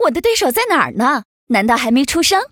文件 文件历史 文件用途 全域文件用途 Choboong_amb_02.ogg （Ogg Vorbis声音文件，长度0.0秒，0 bps，文件大小：32 KB） 源地址:游戏语音 文件历史 点击某个日期/时间查看对应时刻的文件。